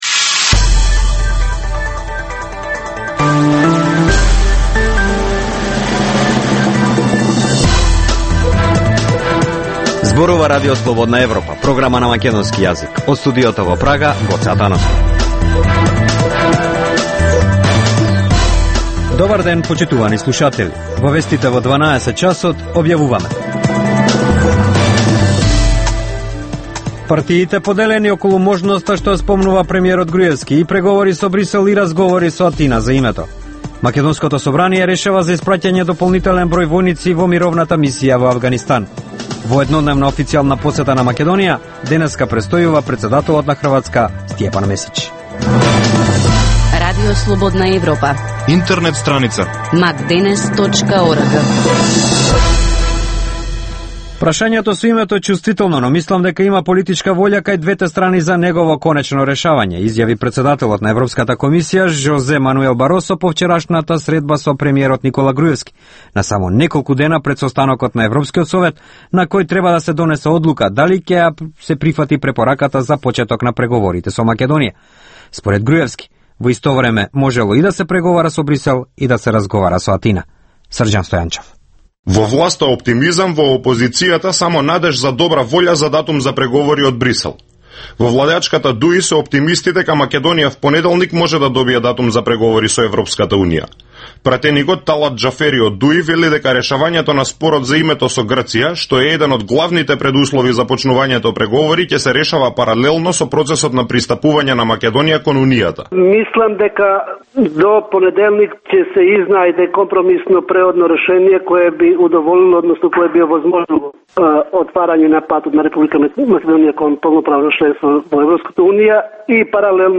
Информативна емисија, секој ден од Студиото во Прага. Топ вести, теми и анализи од Македонија, регионот и светот. Во Вестите во 12 часот доминантни се актуелните теми од политиката и економијата.